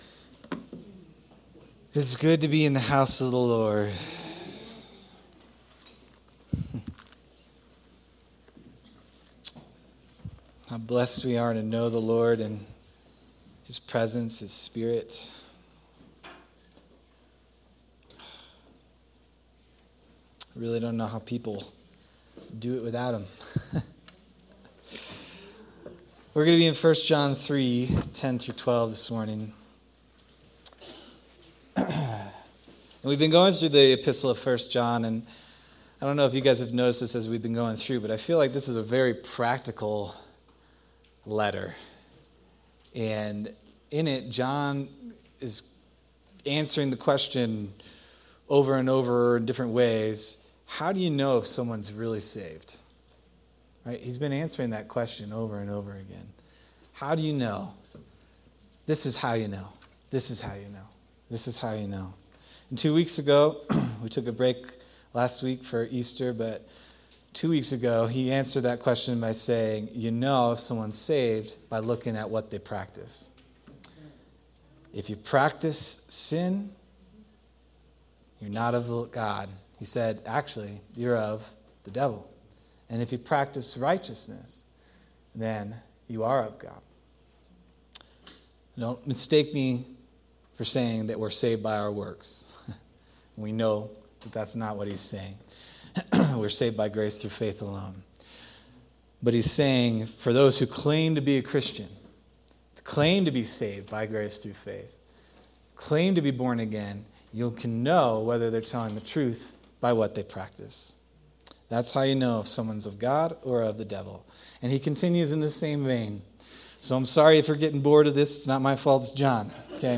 April 12th, 2026 Sermon